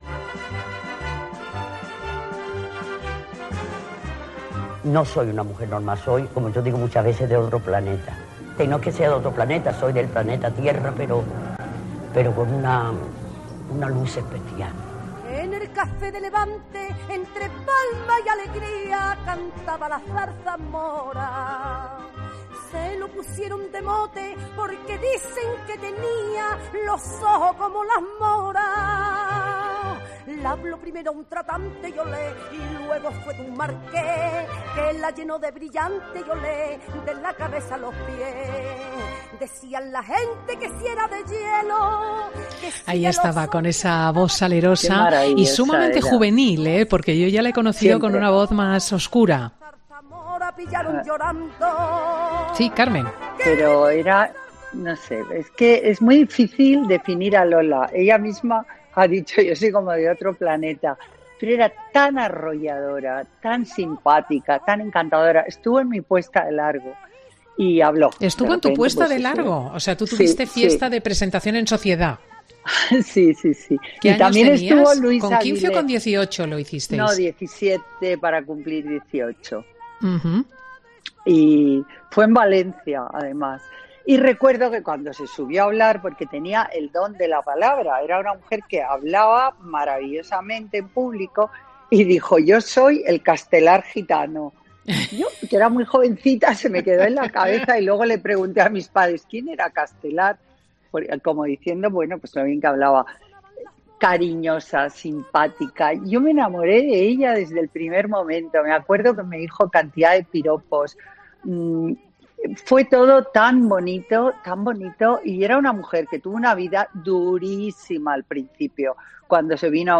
Escucha la entrevista completa en Fin de Semana con Cristina López Schlichting.